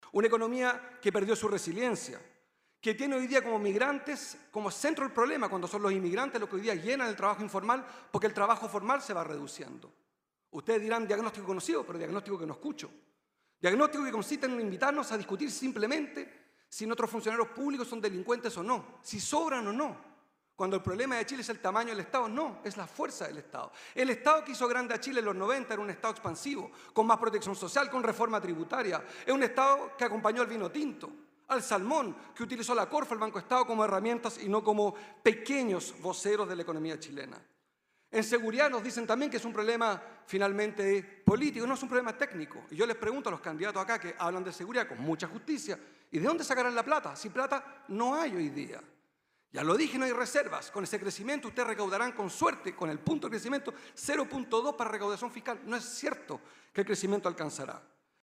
Posteriormente, tomó la palabra el candidato independiente Marco Enríquez-Ominami, cuestionando el estado de la economía actual y señalando que “el Estado que hizo grande a Chile en los ‘90 era un Estado expansivo, con más protección social y reformas tributarias”.